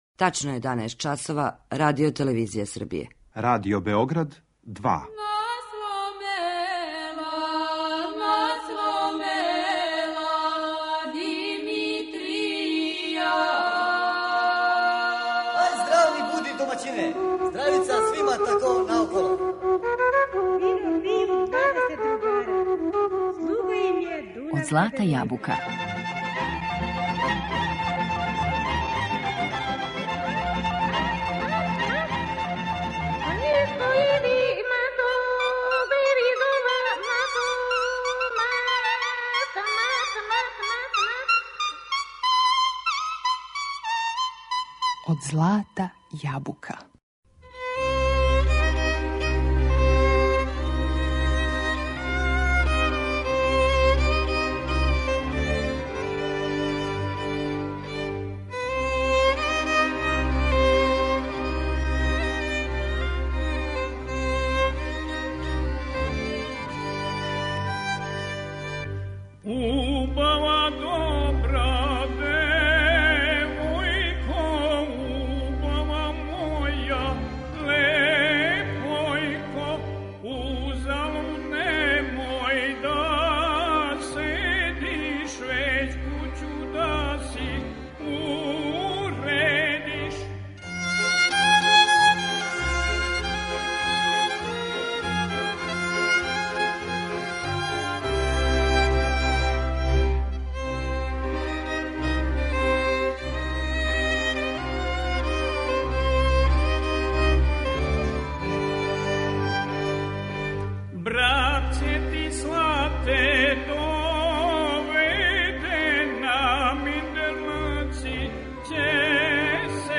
U Tonskom arhivu Radio Beograda sačuvano je sedamnaest njegovih snimaka, a neke od njih čućemo u današnjoj emisiji.